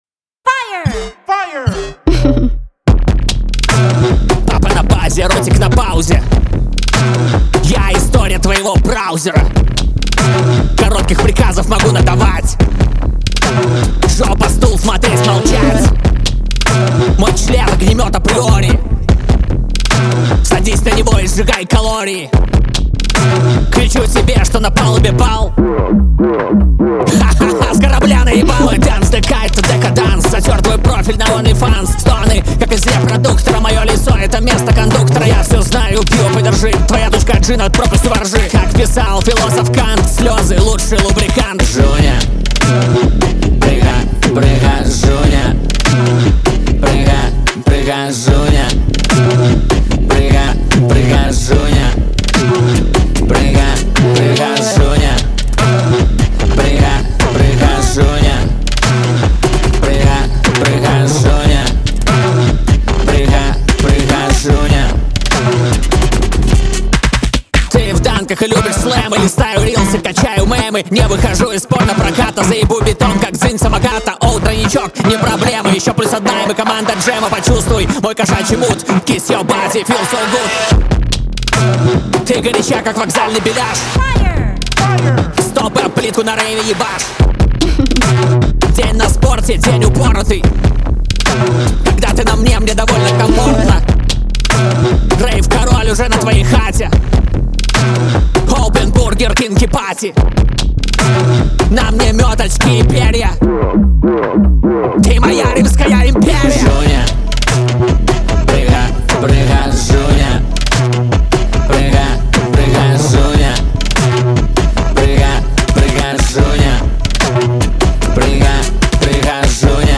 Русская Поп-Музыка